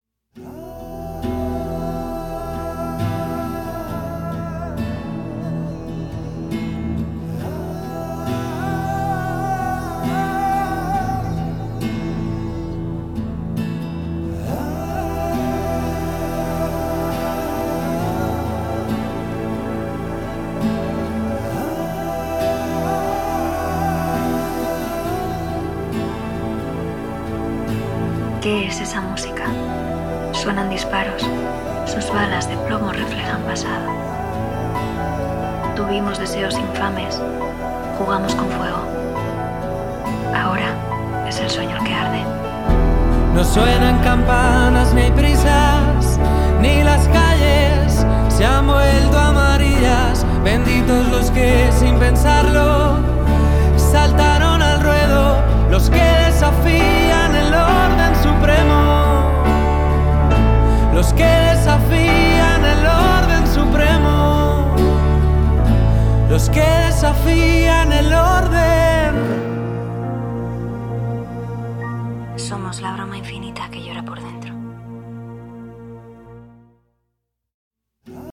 Es un disco más melancólico y más trabajado”, ha explicado.